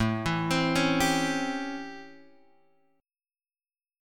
A7#9 chord {5 x 7 6 8 8} chord